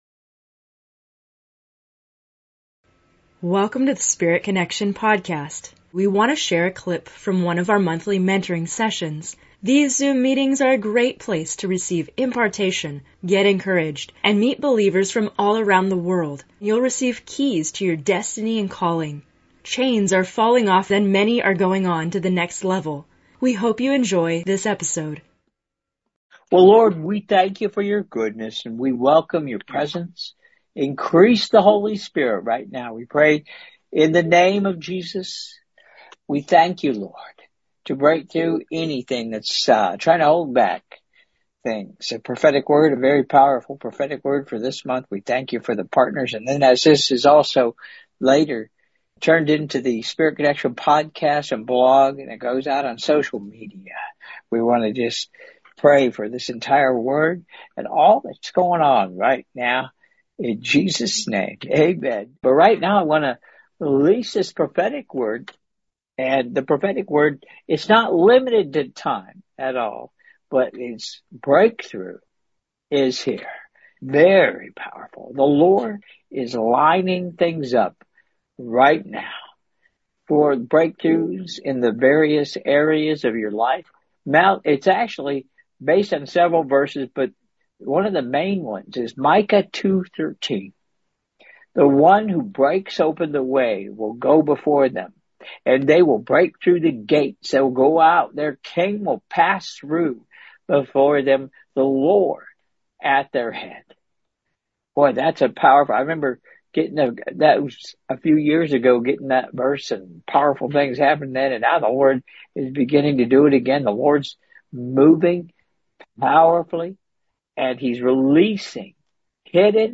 In this episode of Spirit Connection, we have a special excerpt from a recent Monthly Mentoring Session and there is good news!